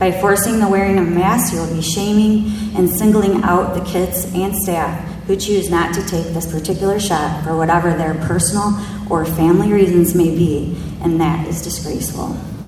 An audience at the Ottawa High School Board meeting tonight asked that there be no mask requirement.